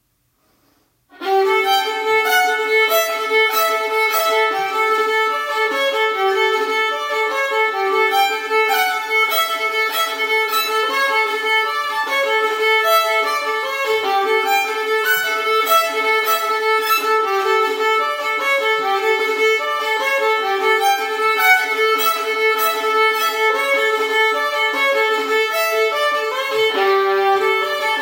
Mimic the Bagpipes!